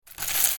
Short Metallic Coins Clink Sound Effect
Short-metallic-coins-clink-sound-effect.mp3